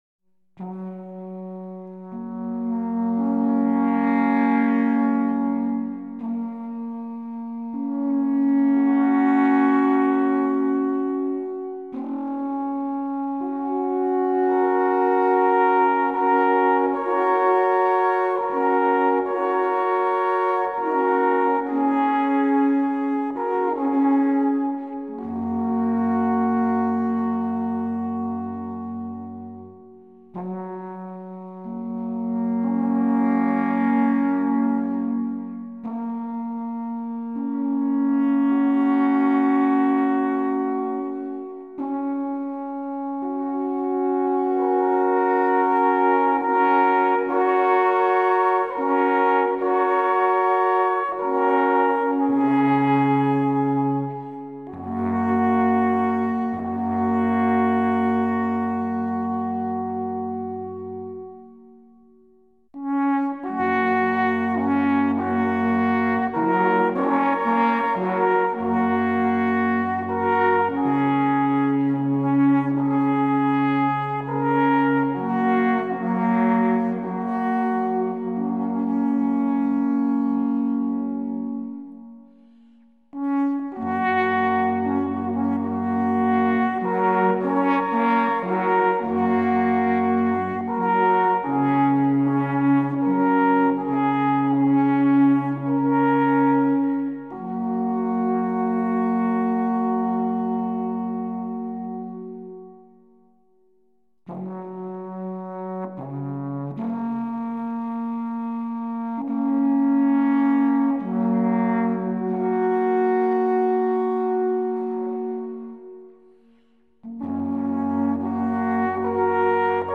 Alphorntrio Guldisberg, Bollodingen: Gruss aus dem Oberaargau – Greetings from upper Argovia